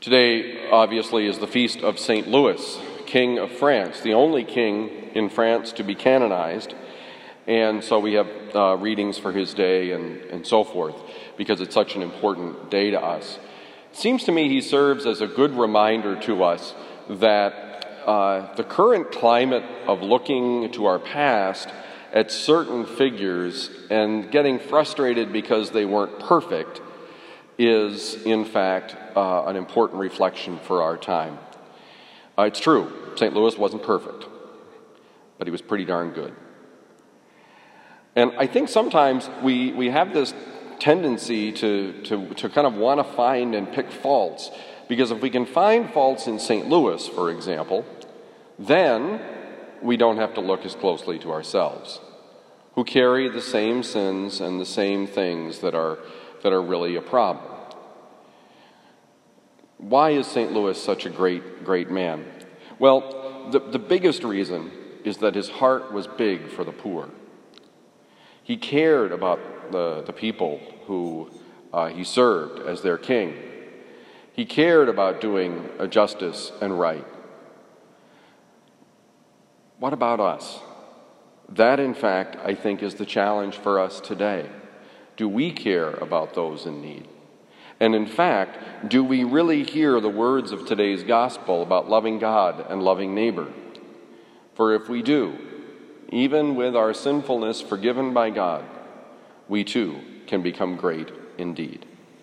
Homily given at Christian Brothers College High School, Town and Country, Missouri